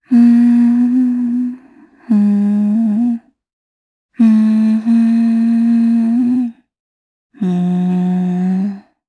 Valance-Vox_Hum_jp_b.wav